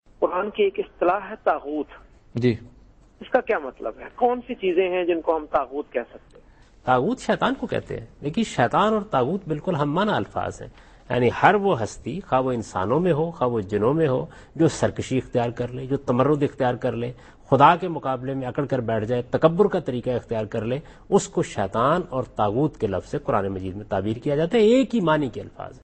Javed Ahmad Ghamidi answer the question "Meaning of Tyrant in the Quran?" in program Deen o Daanish on Dunya News.
جاوید احمد غامدی دنیا نیوز کے پروگرام دین و دانش میں اس سوال "قرآن میں طاغوت کا مطلب؟" کا جواب دے رہے ہیں۔